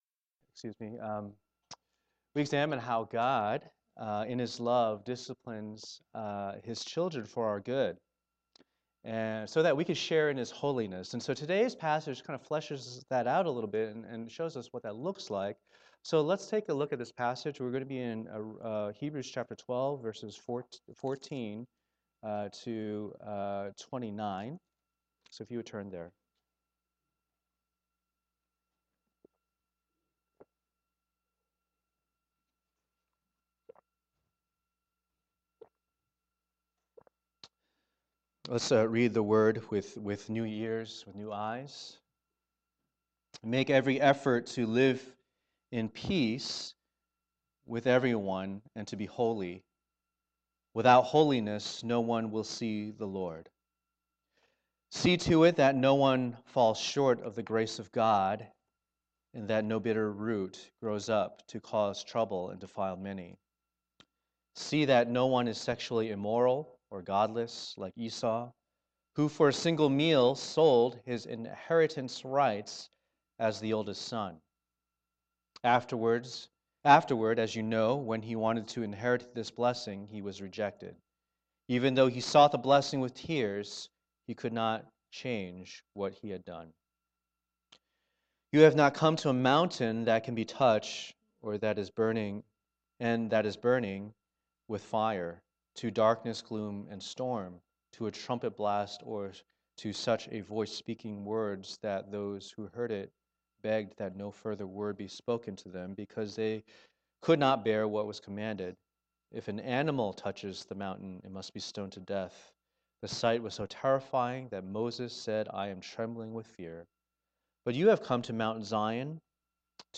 Passage: Hebrews 12:14-29 Service Type: Lord's Day